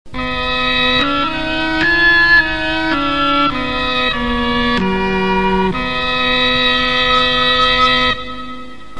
Cor Anglais/Oboe Split
Reedy. Lyrical. Gentle. Pastoral. Expressive. Light.
The lower registers feature the voice of the Cor Anglais (the name of which probably comes from an obscure French Insult from the days of Agincourt) which translates seamlessly into a beautiful new recording of an oboe.